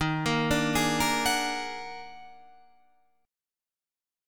D# Minor Major 11th